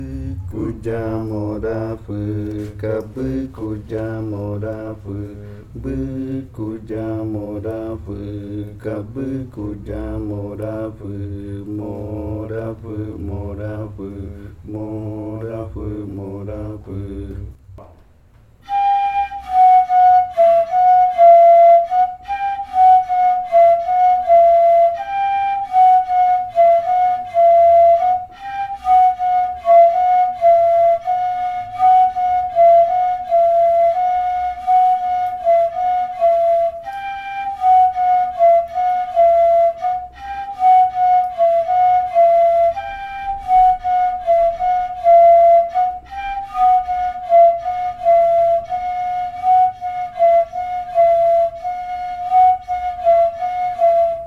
Leticia, Amazonas, (Colombia)
Grupo de danza Kaɨ Komuiya Uai
Canto Bɨkuya morafɨ (lengua ocaina) e interpretación del canto en pares de reribakui.
Bɨkuya morafɨ chant (ocaina language) and performance of the chant in reribakui flutes.